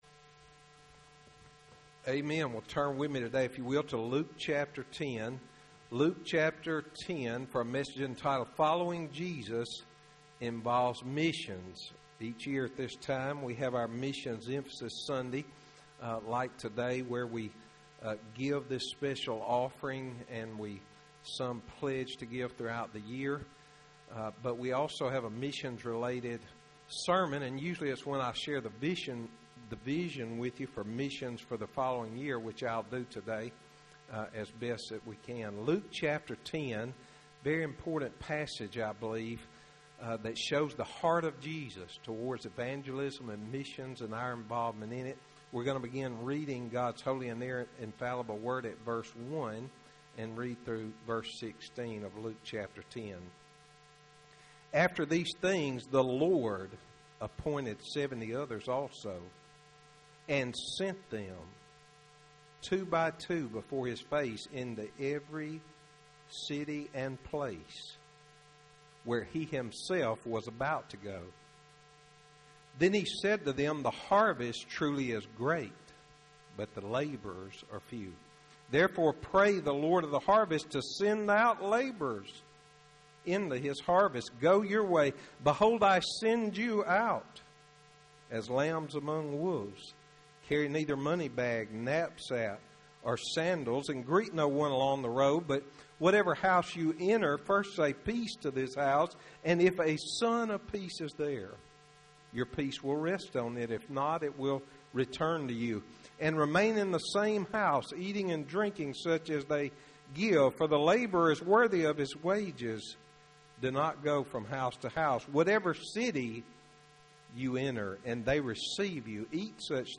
Sermons - Highland Baptist Church